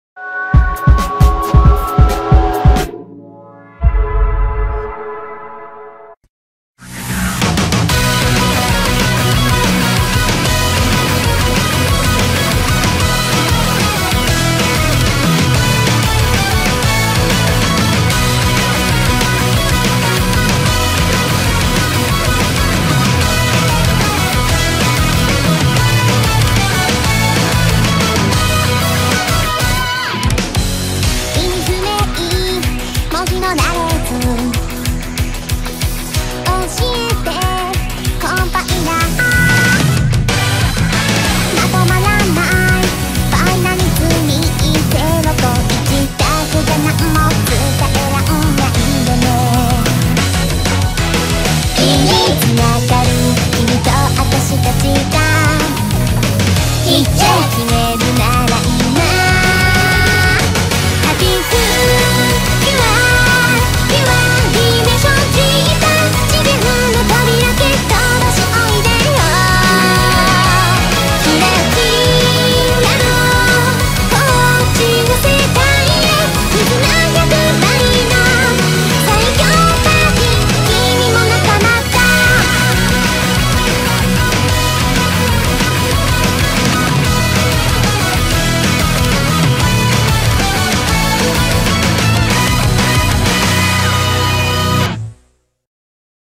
BPM188
Audio QualityCut From Video